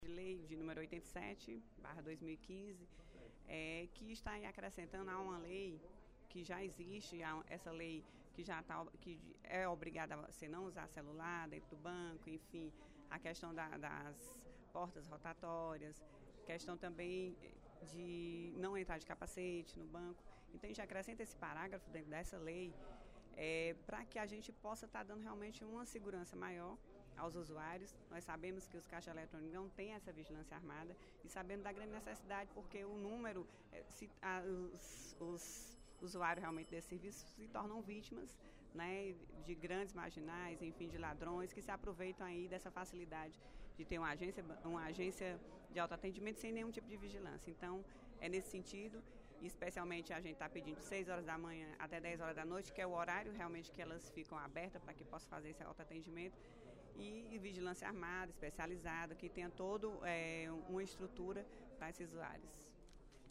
A deputada Augusta Brito (PCdoB) discursou, durante o segundo expediente da sessão plenária desta quinta-feira (13/08), sobre o projeto de lei nº 87/15, de sua autoria, que acrescenta artigo à Lei 14.961, que dispõe sobre a proibição do uso de celulares e outros itens nas agências bancárias de todo o Estado.